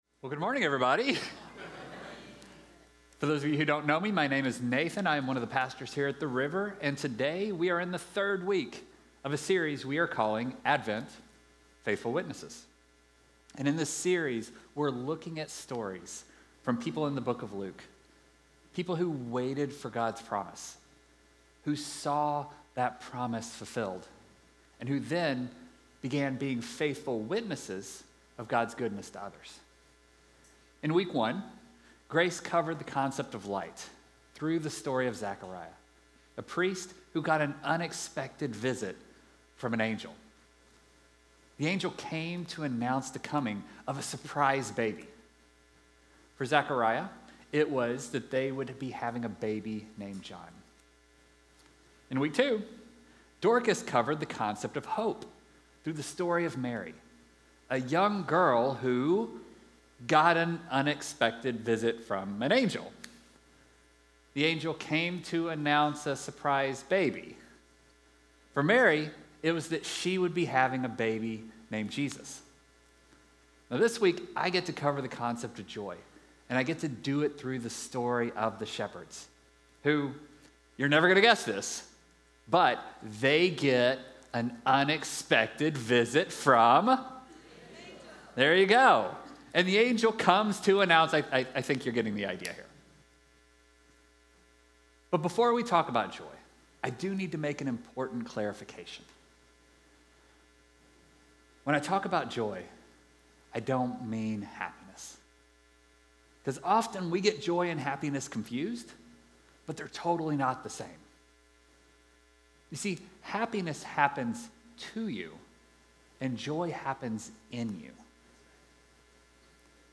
The River Church Community Sermons Advent